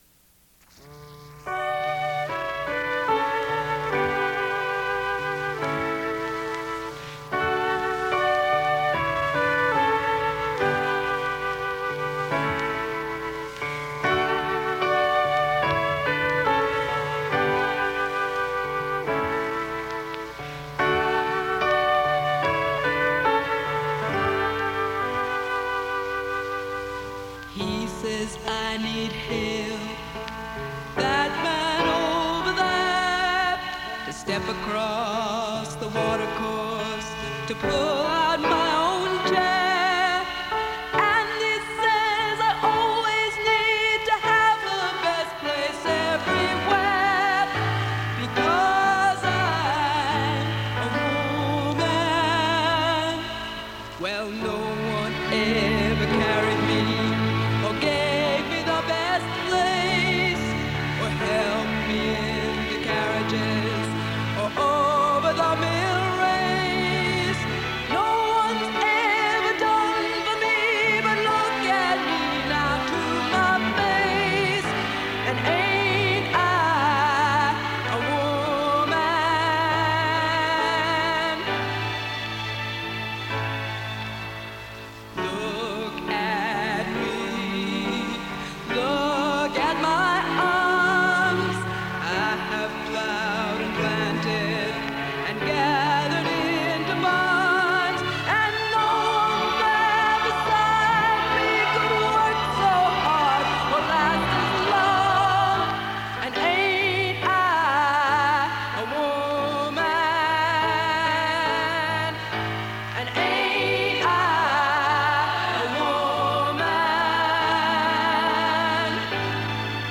WBAI Radio Programs, circa 1970s